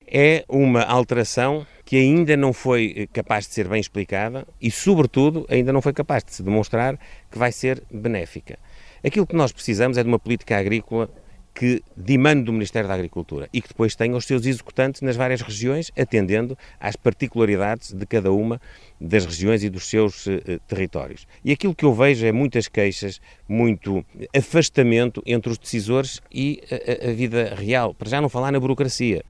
Declarações de Luís Montenegro, em Mirandela, em agosto de 2023, na altura como líder da oposição.